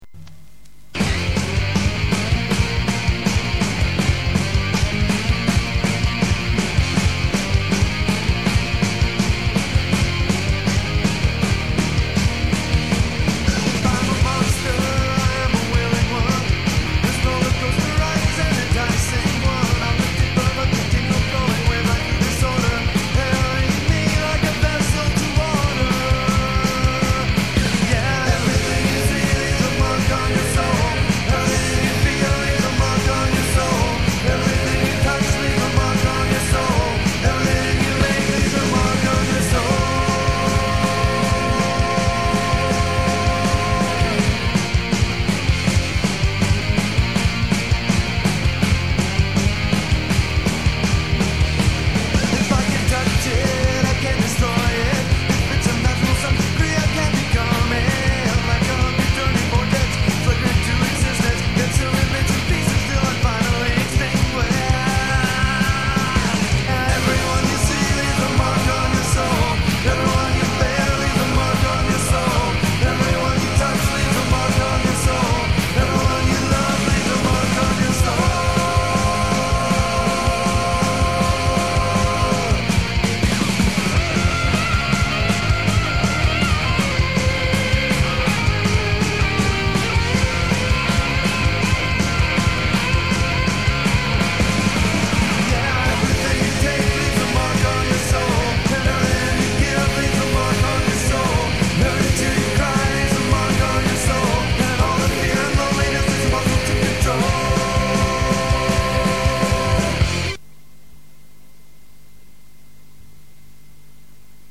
home demos